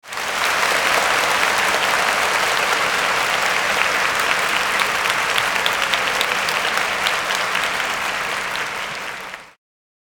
Download Applause sound effect for free.
Applause